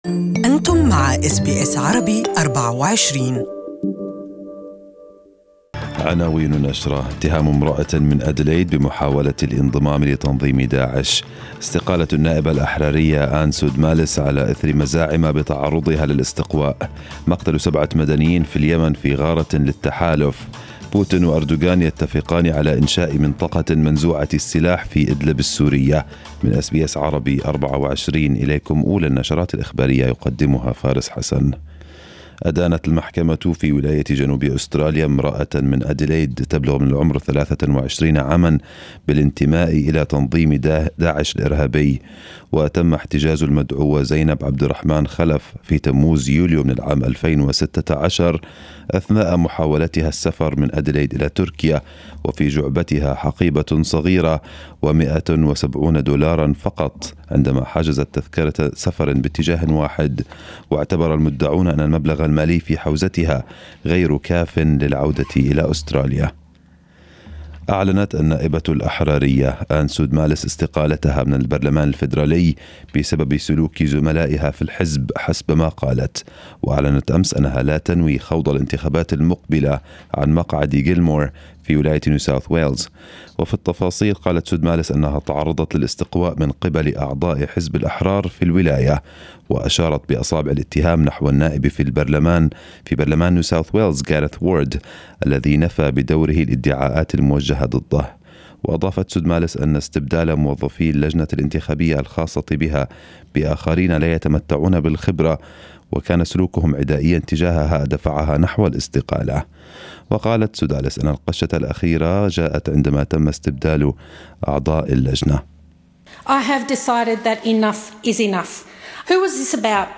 More news in our first news bulletin this morning in Arabic